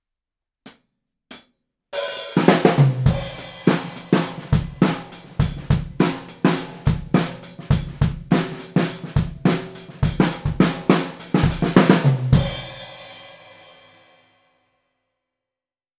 【サンプル音源あり】MXL V67G 一本のみでドラムレコーディング！
最後に、ドラマーからの目線でマイキングしました。
ドラマーのちょうど頭の高さぐらいの位置です。
すこし、重心の下がった印象のサウンドになりました。